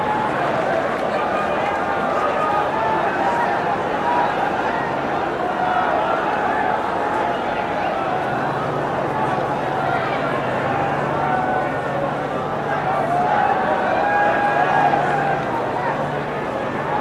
snd_audience_idle.ogg